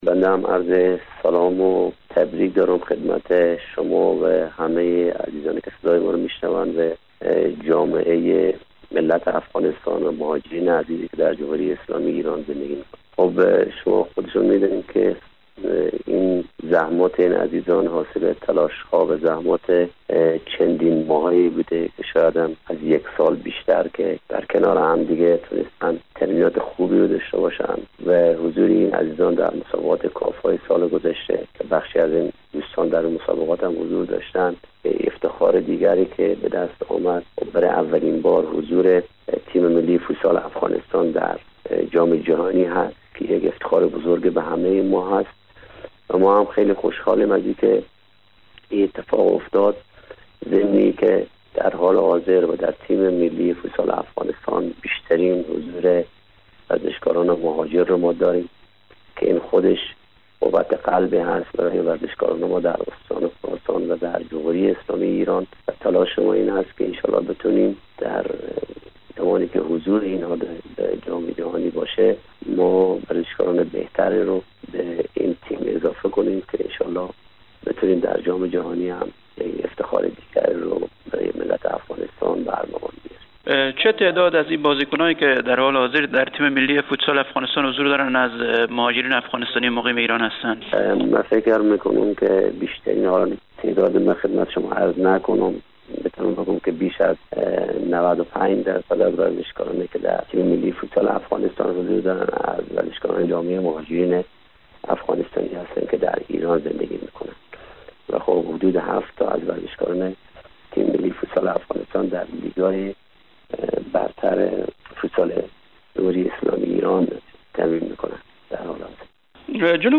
گفت و گو با برنامه انعکاس رادیو دری